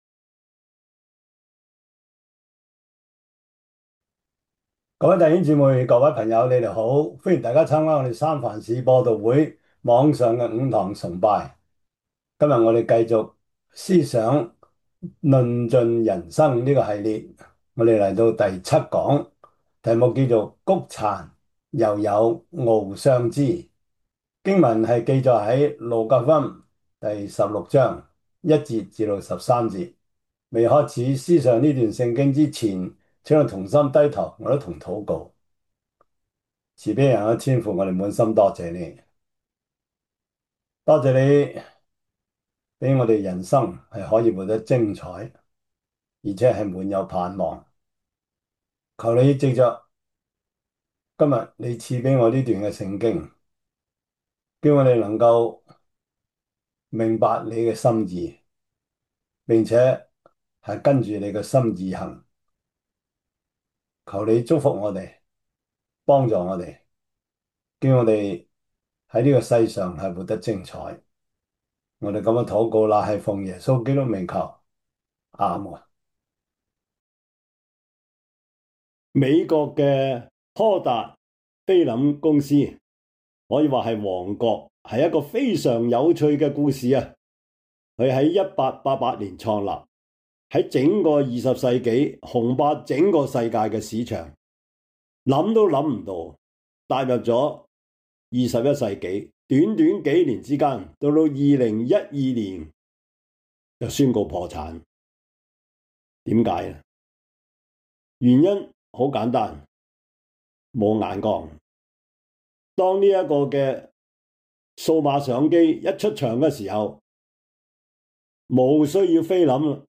路加福音 16:1-13 Service Type: 主日崇拜 路加福音 16:1-13 Chinese Union Version